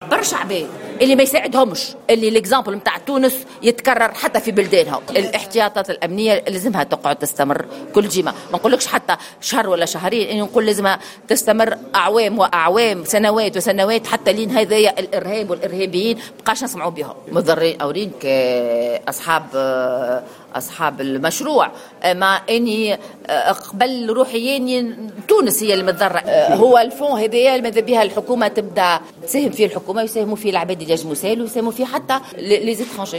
وبينت النائبة بمجلس نُواب الشعب عن نداء تونس في تصريح لجوهرة أف أم أن تونس تضررت من هذه العملية وأن الاحتياطات الأمنية يجب أن تستمر الى حين القضاء على الارهاب مشيرة الى أن الكثيرين لا يريدون للتجربة الديمقراطية في تونس أن تنجح على حد قولها.